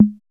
CR78 CGA LO.wav